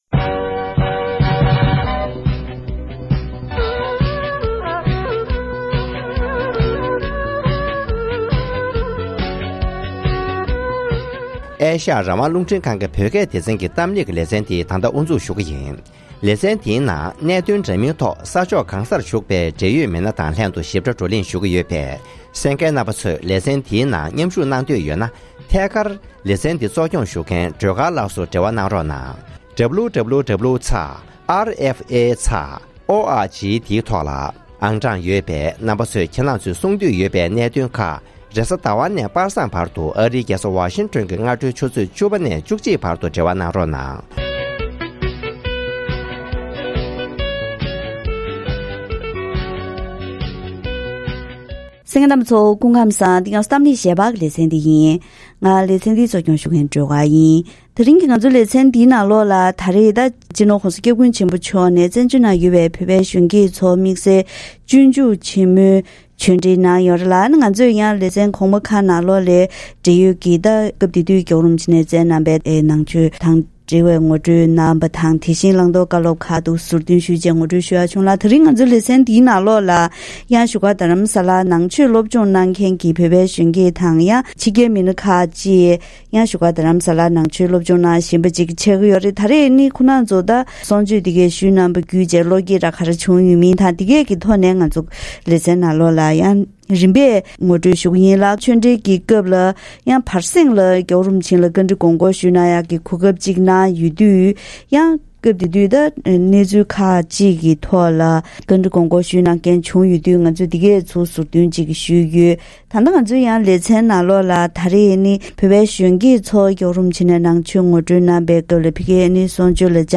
༄༅༎དེ་རིང་གི་གཏམ་གླེང་ཞལ་པར་ལེ་ཚན་འདིའི་ནང་བཞུགས་སྒར་རྡ་རམ་ས་ལར་ནང་ཆོས་སློབ་སྦྱོང་གནང་མཁན་བོད་པའི་གཞོན་སྐྱེས་ཞིག་དང་ཕྱི་རྒྱལ་གྱི་བཙུན་མ་ཞིག་ལྷན་དུ་༧གོང་ས་མཆོག་གི་གསུང་ཆོས་ཞུས་པ་བརྒྱུད་བློ་བསྐྱེད་ཇི་བྱུང་དང་།